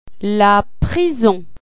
La prison   kuk